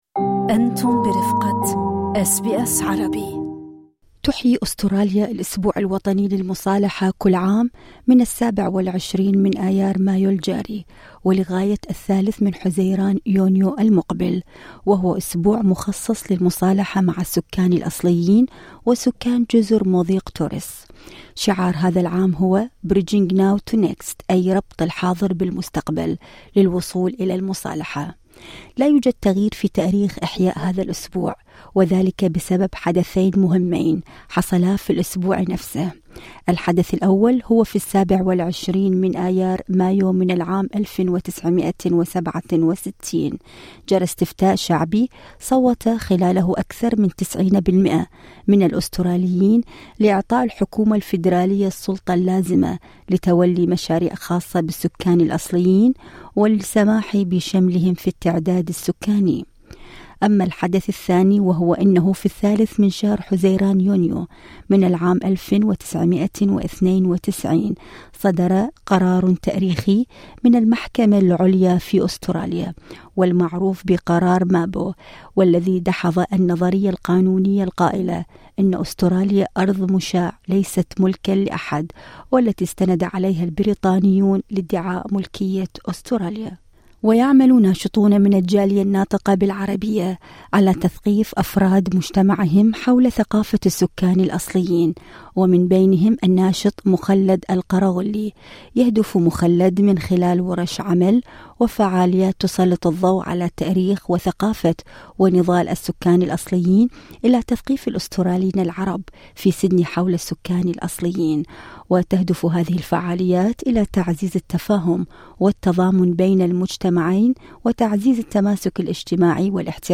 نستمع خلال الساعة الأولى الى تقرير يتضمن لقاءً مع الناشط في قضايا السكان الاصليين